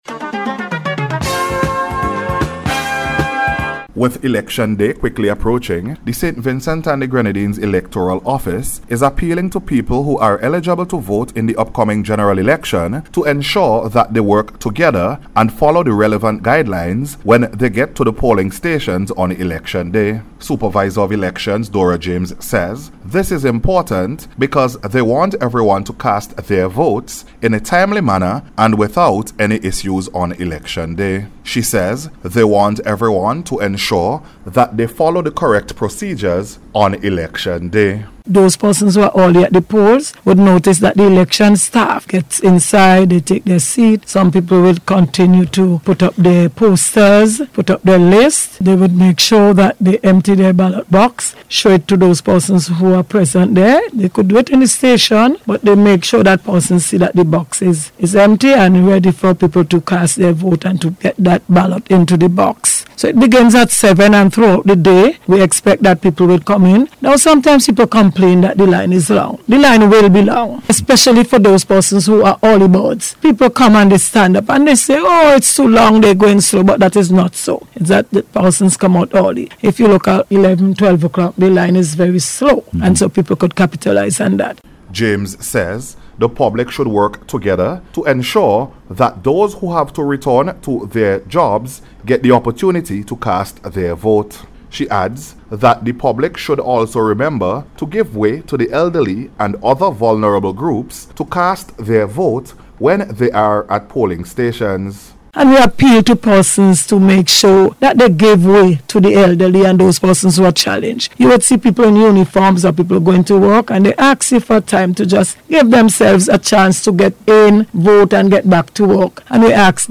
EVERYONE-WORK-TOGETHER-ON-ELECTION-DAY-REPORT.mp3